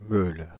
Meulles (French pronunciation: [møl]
Fr-Meulles.ogg.mp3